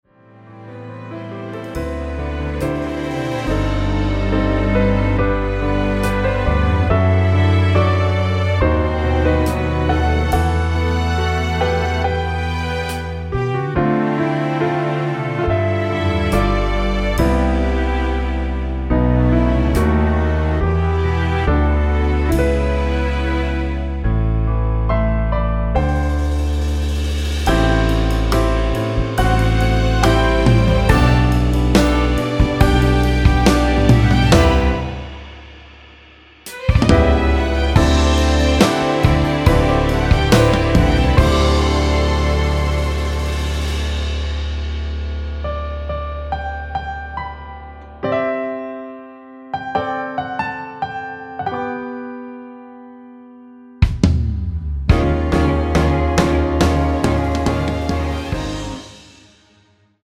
1절 “정말 고마워” 다음 후렴부분인 “밤에 울다 잠이 깼을 때”로 진행 되게 편곡하였습니다.
1분 미리듣기는 “너라는 사람이” 부터 “이게 내 진심이야”까지 입니다.
Bb